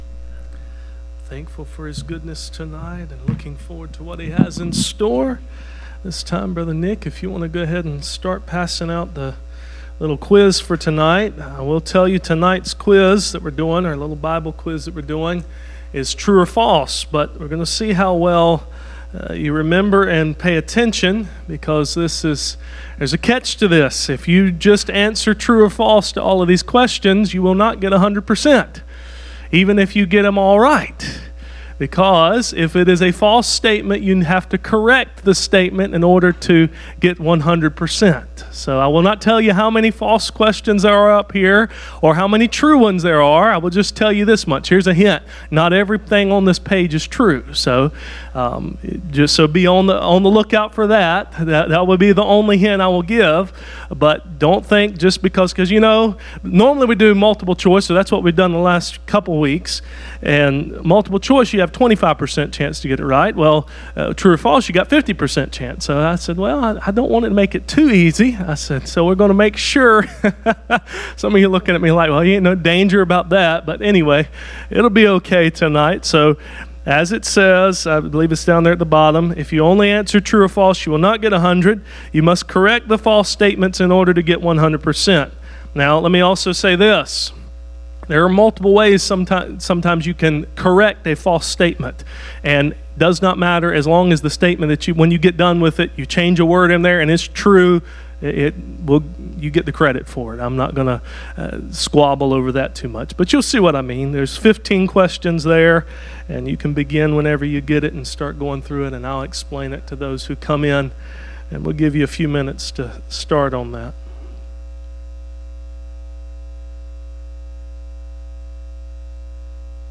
Passage: Numbers 15-19 Service Type: Midweek Meeting « The prayer for glory